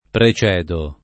precedere [pre©$dere] v.; precedo [